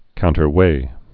(kountər-wā)